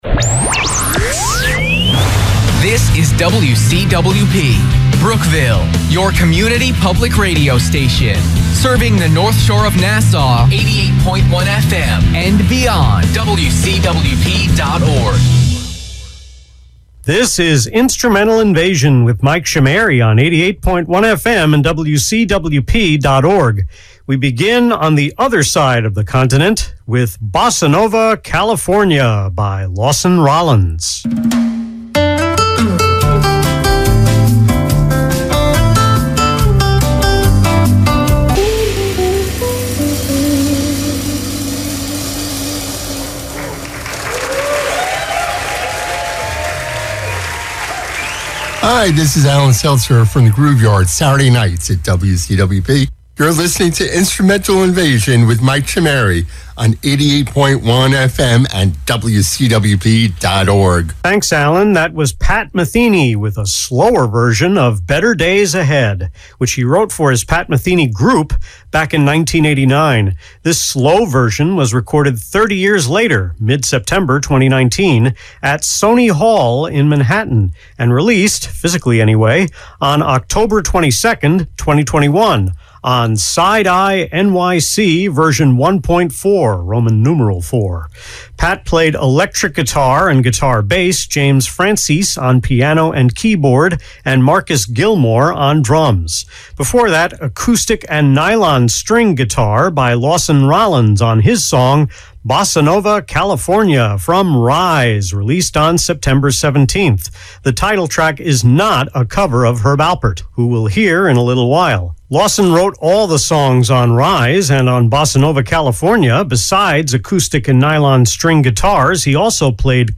The December 15 Instrumental Invasion on WCWP was recorded its entirety at my remote location on November 1, immediately after annotating the playlist and drafting the script.
To play it safe, I applied the denoise filter to all talk breaks, except for the pickup I recorded from home which is the first time I noticed how echoey my room is.